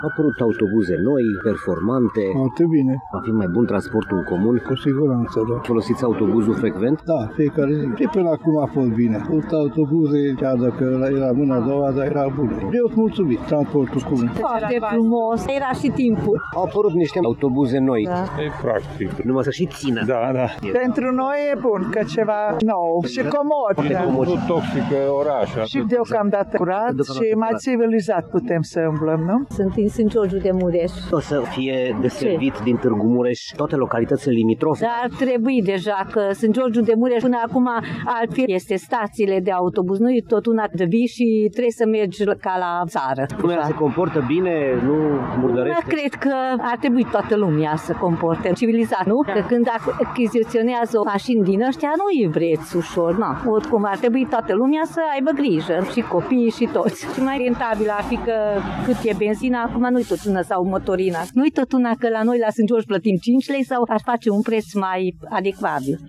Târgumureșenii s-au declarat în general mulțumiți de modul în care s-a organizat, în ultimii ani, transportul local, iar apariția autobuzelor noi nu face decât să crească confortul acestui transport: